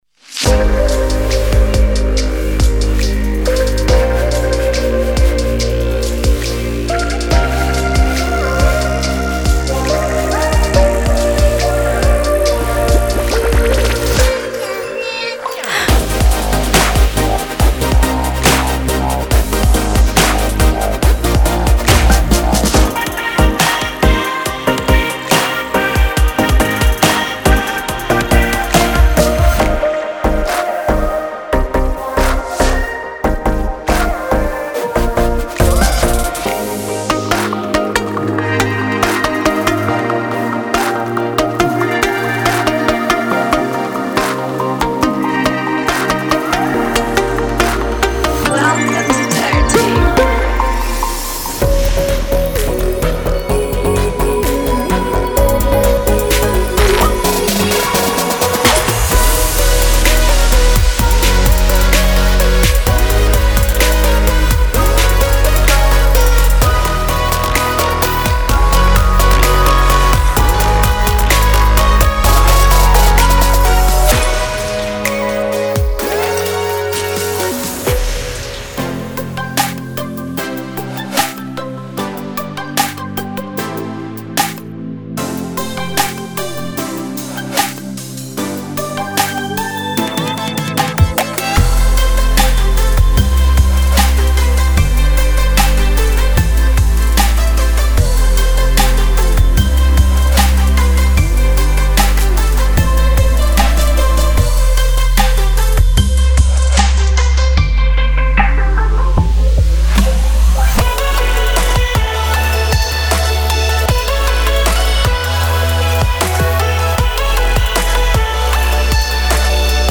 It has a very colorful palette of sounds, which can inspire.
- Colorful and modulating synth + chord sounds, catchy vocal-style leads, crisp bass and plucky sweet keys
- Dynamic sounds that mix the different types of synthesis. This results in a distinct and fresh, organic - synthetic hybrid type of sound
This set will work best for Future Pop, Future Bass, Future RnB + Trap, Chillout, EDM, Kawaii and several house genres, but can come in handy in many other music genres as well.